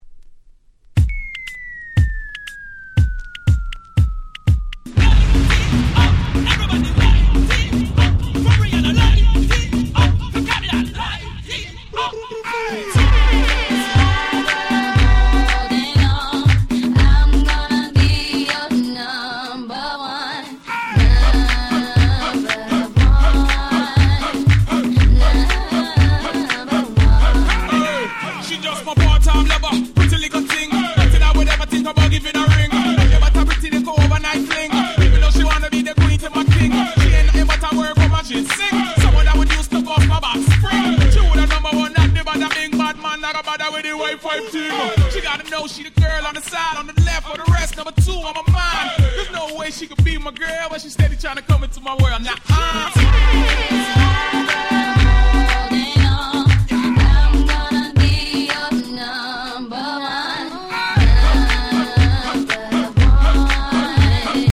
夏ソング アゲアゲ EDM レゲエ Reggae 00's R&B